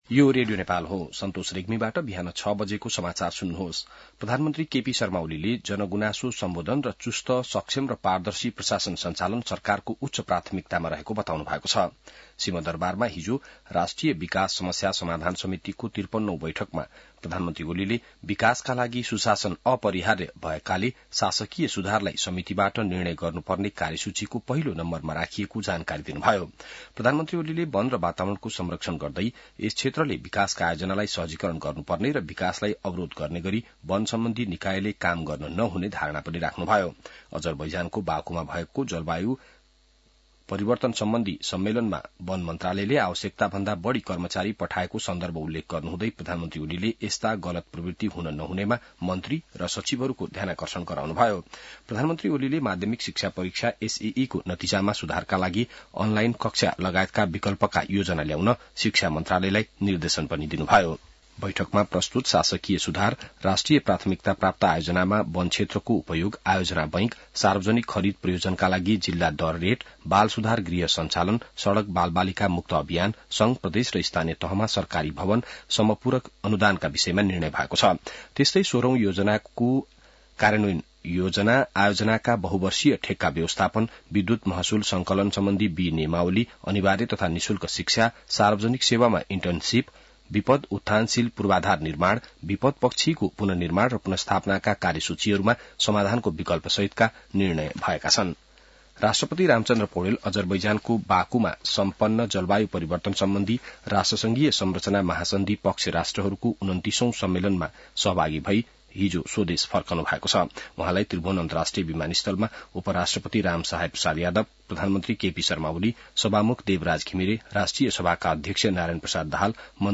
बिहान ६ बजेको नेपाली समाचार : २ मंसिर , २०८१